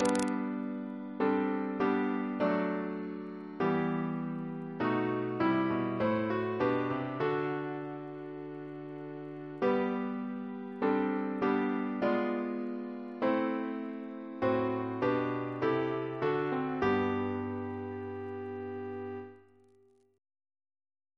Double chant in G Composer: George Mursell Garrett (1834-1897), Organist of St. John's College, Cambridge Reference psalters: ACB: 330; OCB: 19